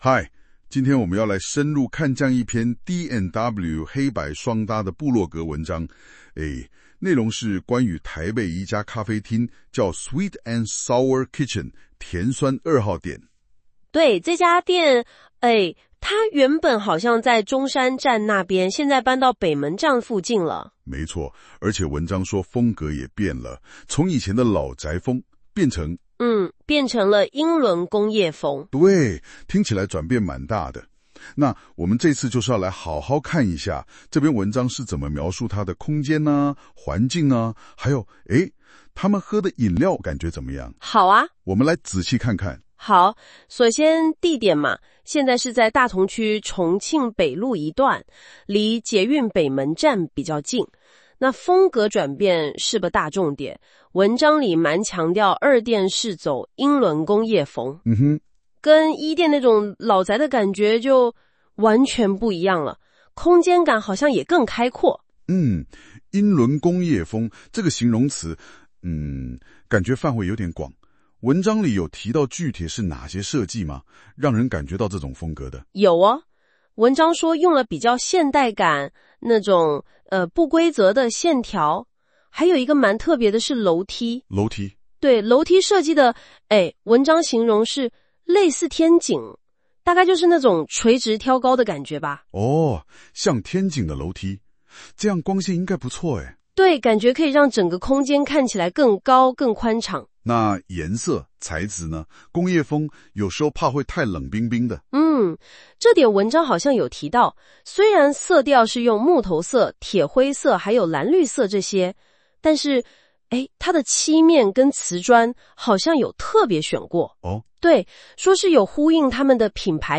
新功能!現在用【說】的方式介紹文章哦!
我們請兩位主持人專業講解，介紹D&W黑白雙搭本文章